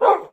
bark1.ogg